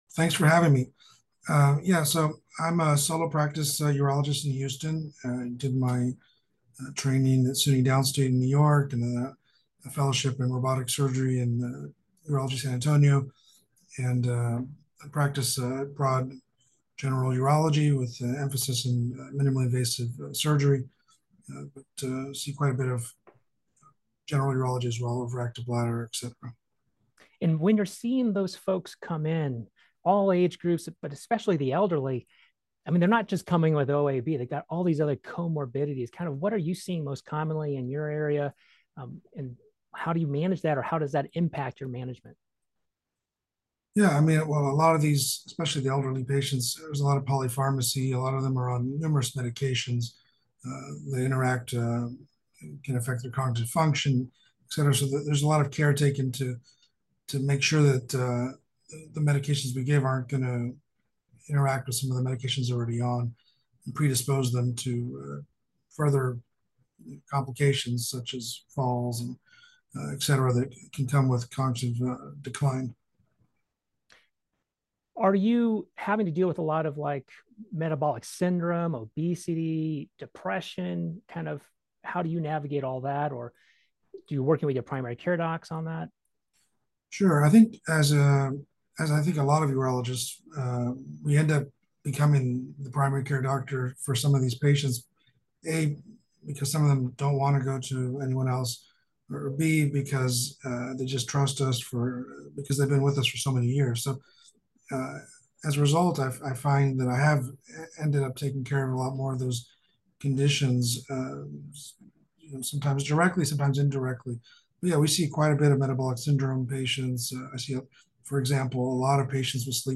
Panelists discuss how managing overactive bladder in older adults requires a holistic approach that integrates treatment of comorbidities, emerging therapeutic options, and quality-of-life considerations to optimize patient outcomes.